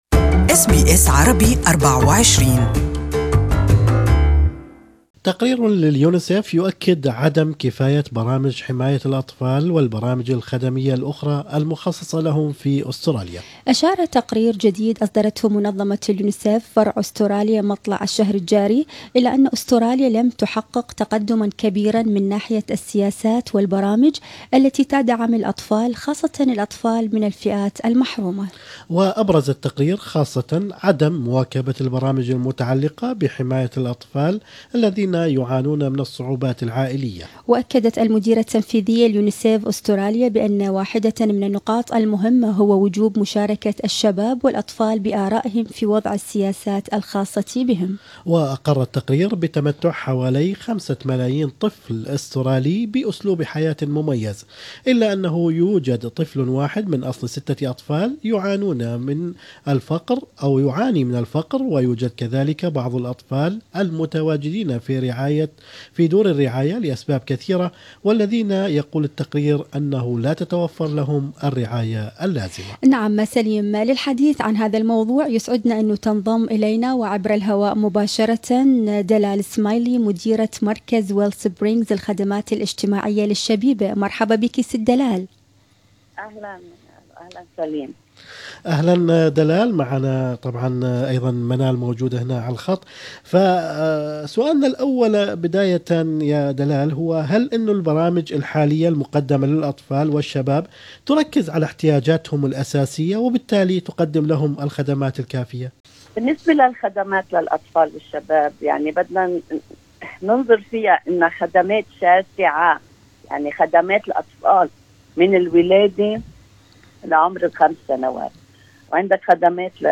وللتعليق عن هذا الموضوع كان لنا هذا اللقاء